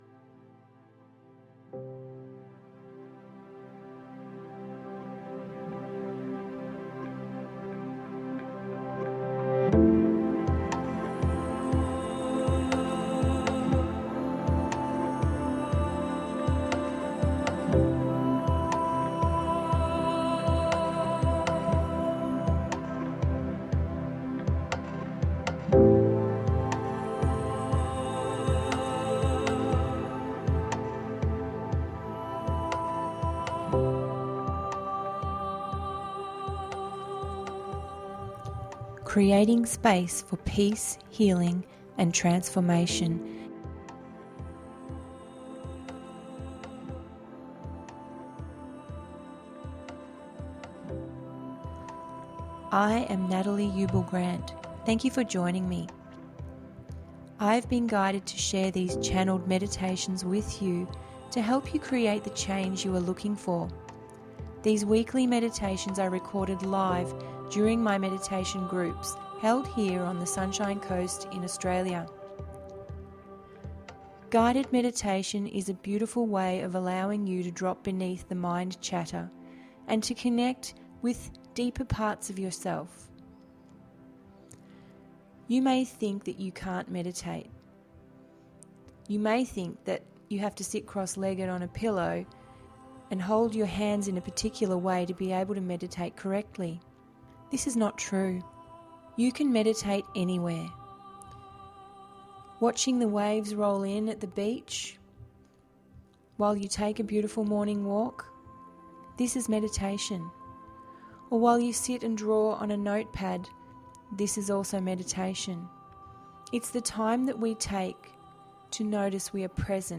Relax and Manifest Your Heart Space…072 – GUIDED MEDITATION PODCAST